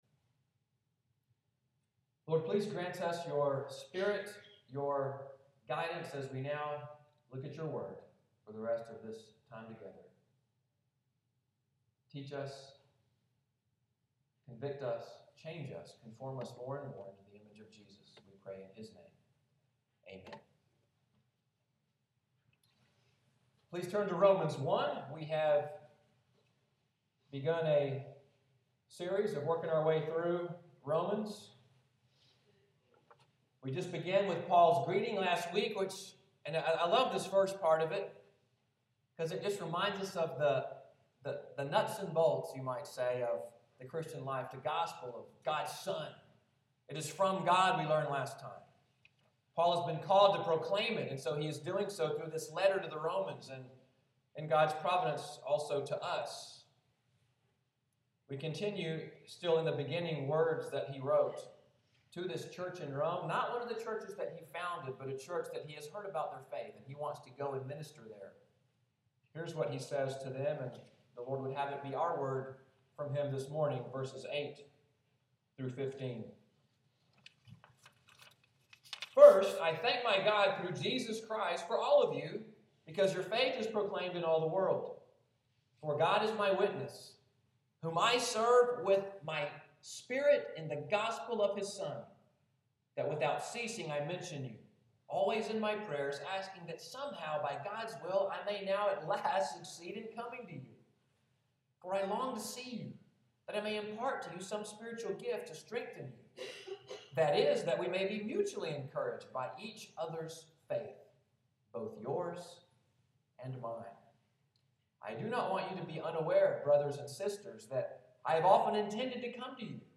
Sunday’s sermon, “Apostolic Longing,” January 25, 2015.